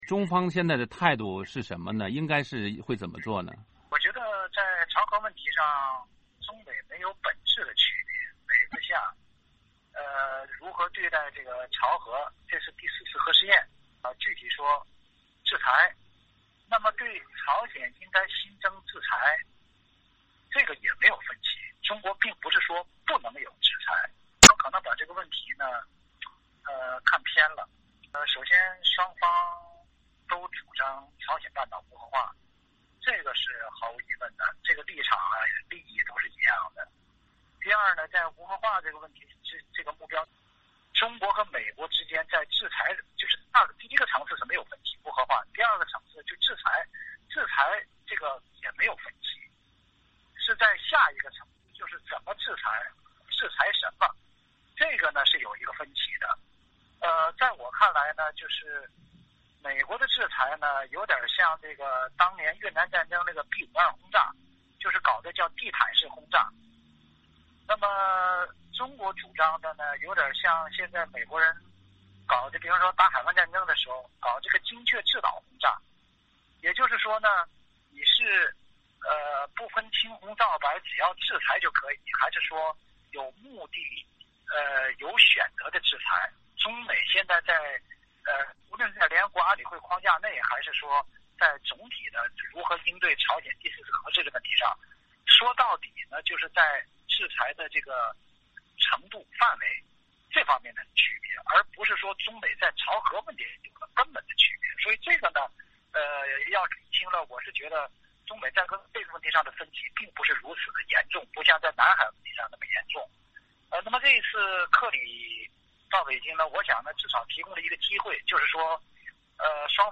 VOA专访：中国专家谈朝核及美中立场异同(上)